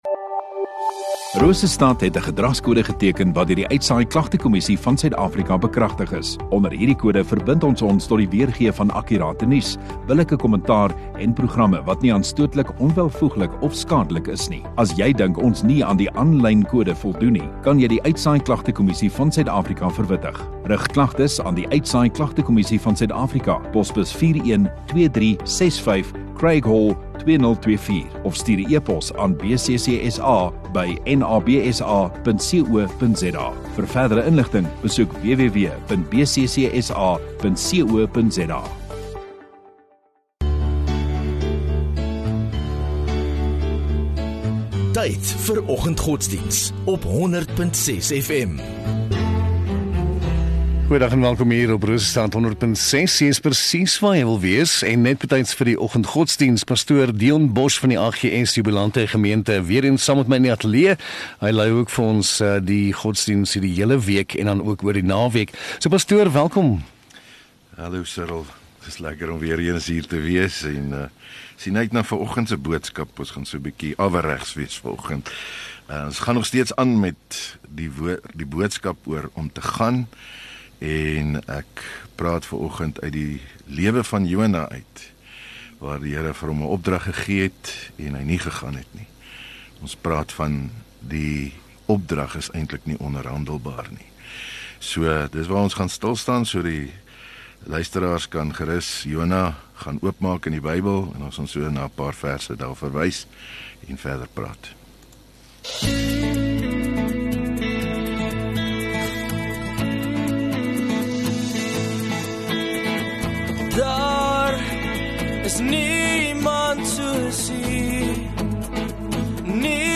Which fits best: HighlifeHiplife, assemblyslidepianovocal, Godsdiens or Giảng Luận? Godsdiens